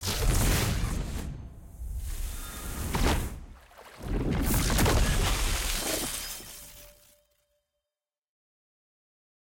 sfx-exalted-rolling-ceremony-single-anim.ogg